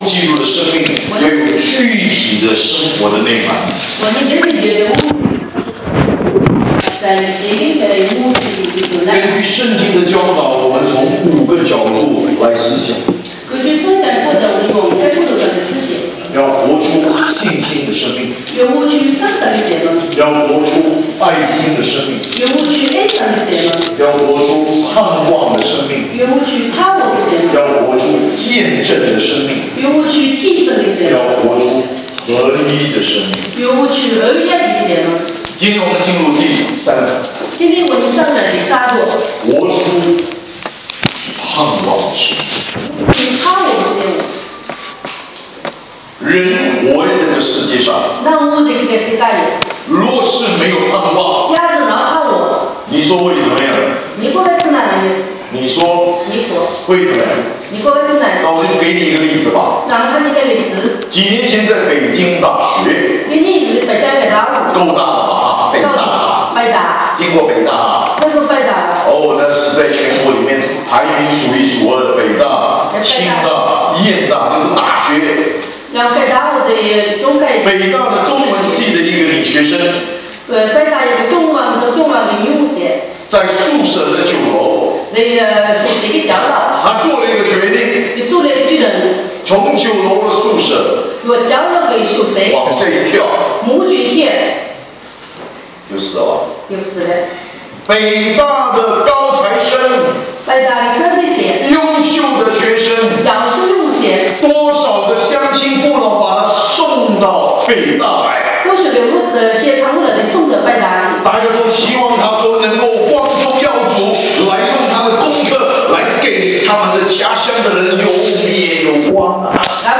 巴黎温州教会2014年春季培灵会（音频）